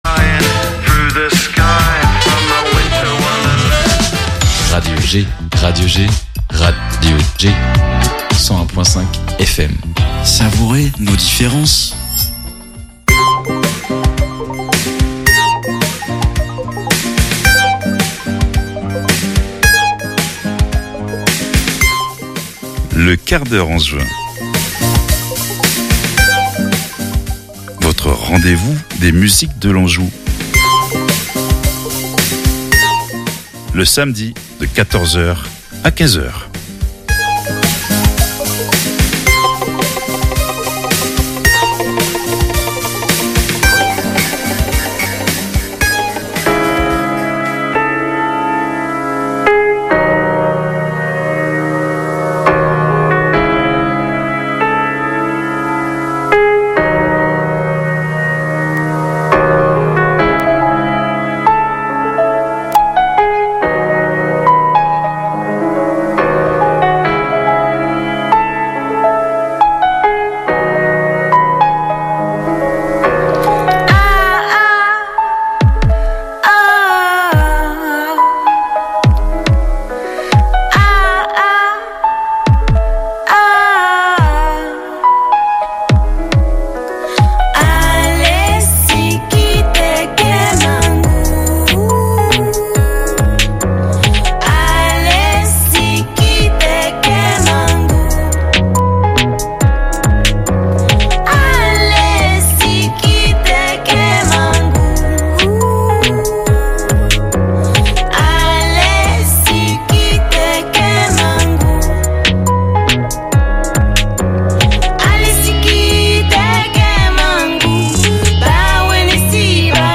Pendant une heure, nous vous offrons un voyage sensible qui va du rock à la chanson française, en passant par le rap, le jazz, le blues ou les musiques du m